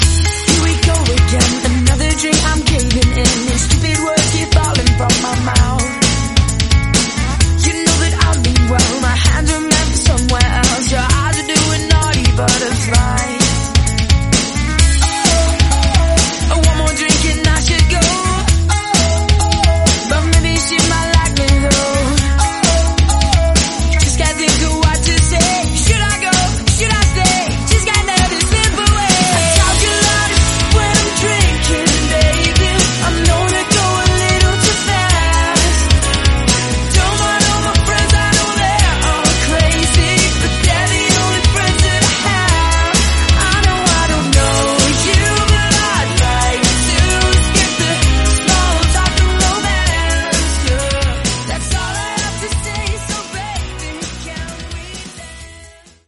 Genres: 2000's , EDM , FUTURE HOUSE , TECH HOUSE
Clean BPM: 128 Time